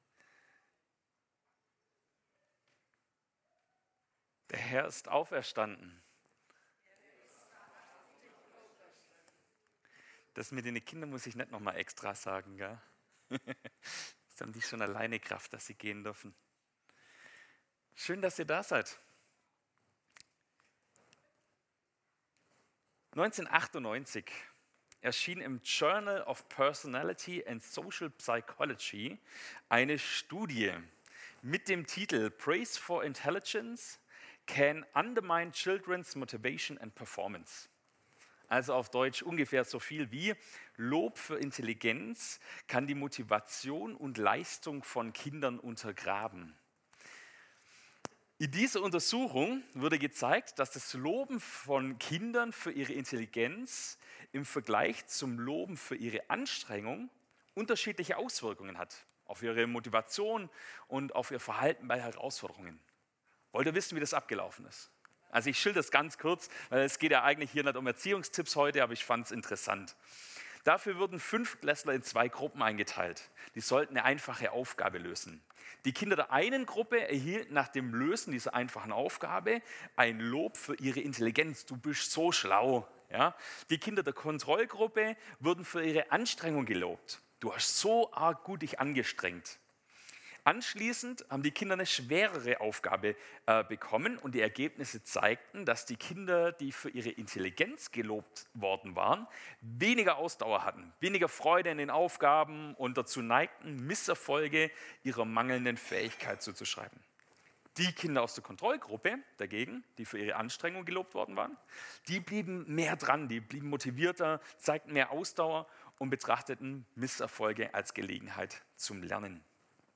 Ostersonntagsgottesdienst am 20.04.2025